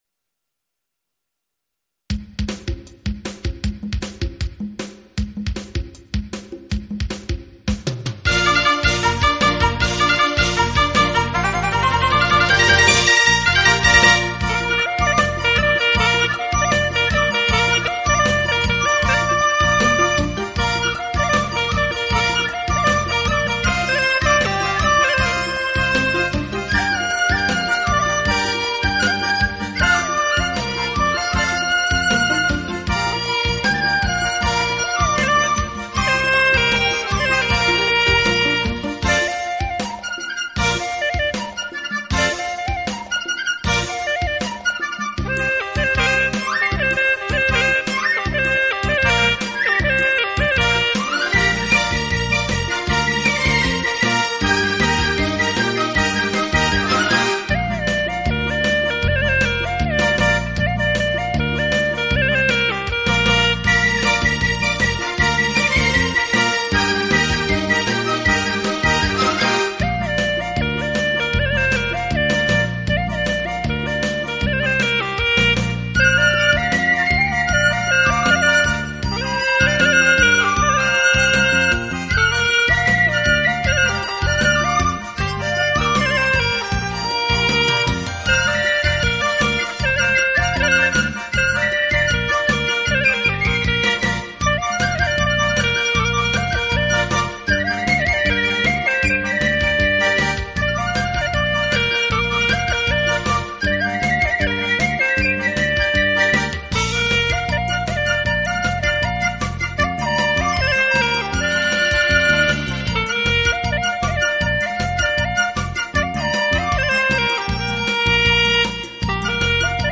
0066-葫芦丝曲：傣家欢歌.mp3